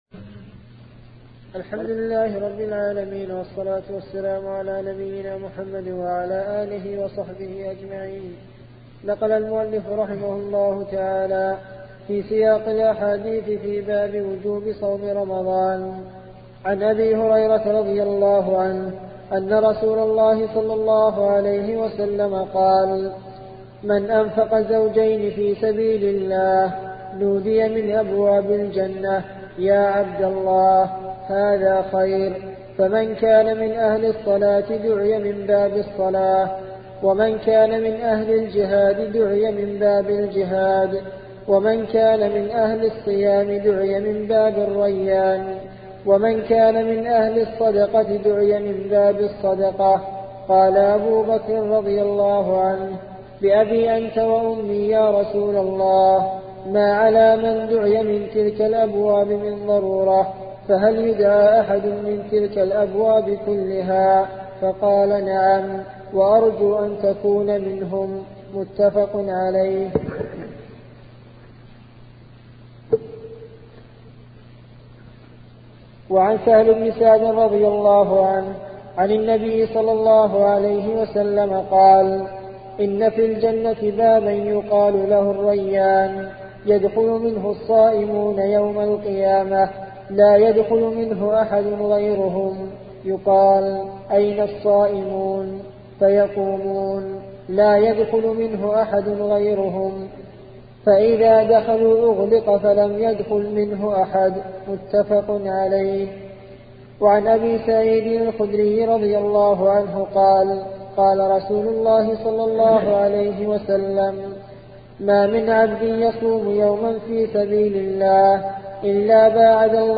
سلسلة مجموعة محاضرات شرح رياض الصالحين لشيخ محمد بن صالح العثيمين رحمة الله تعالى